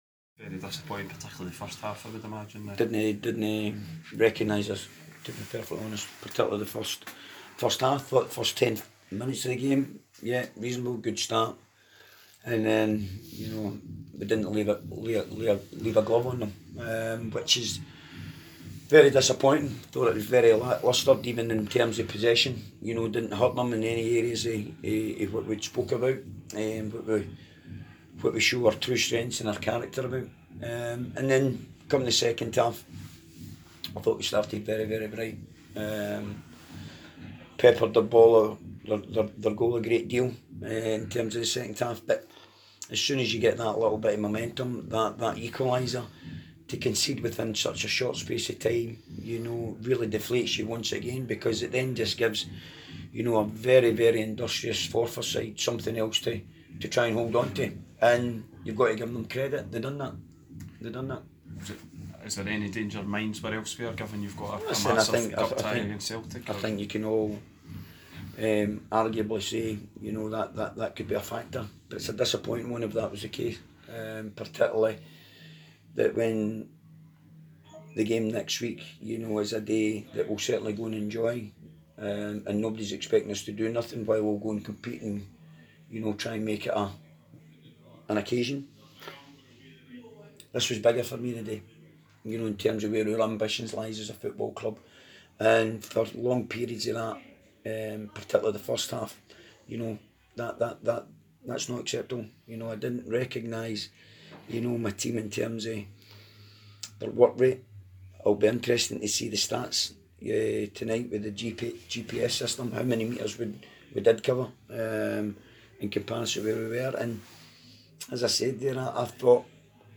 press conference after the Ladbrokes League 1 match.